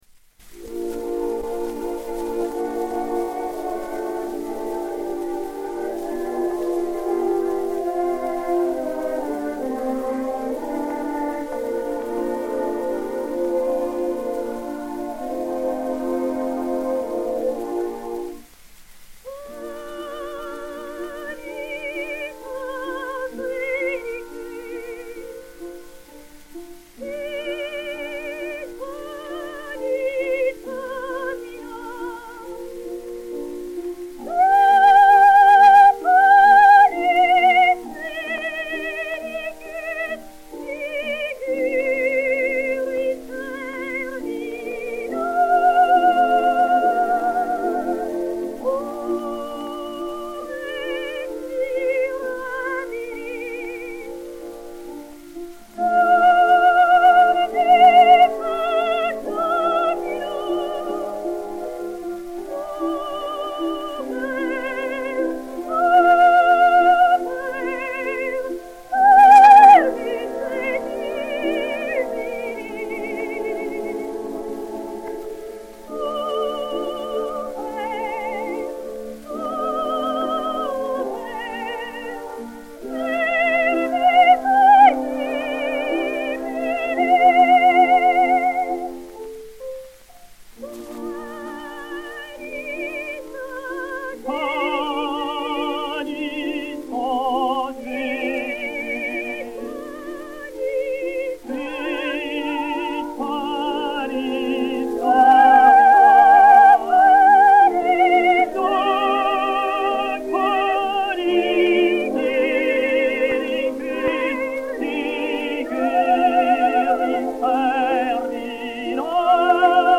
Cavatine "Anges du paradis"
Léon Beyle (Vincent) et Orchestre